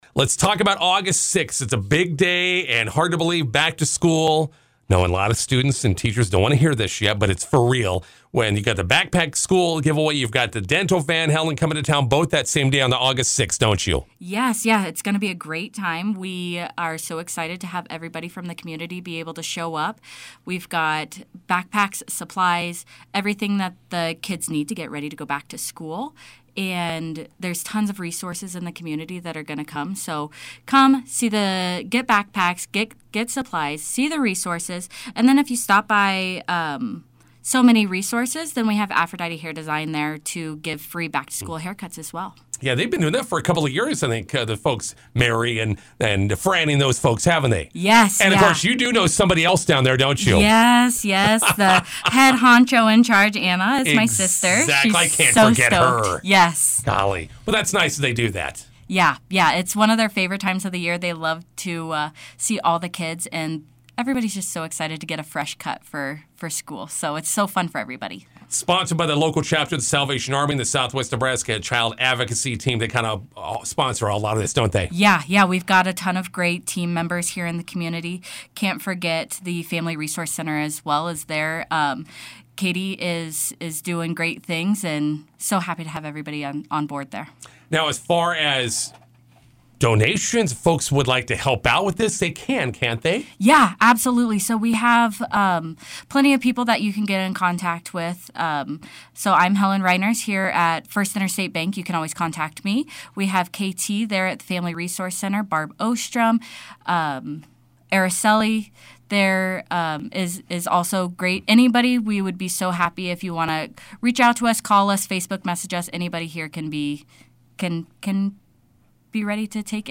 INTERVIEW: Salvation Army annual backpack giveaway coming up on August 6th at McCook Christian Church.